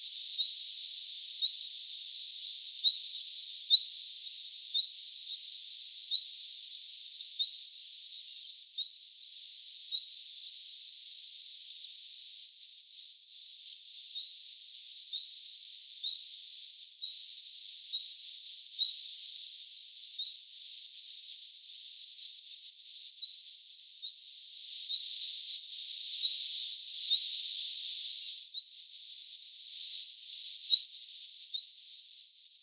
hyit-tiltaltin_huomioaantelya_yksi_aani_joka_muistuttaa_hiukan_zuit-aanta.mp3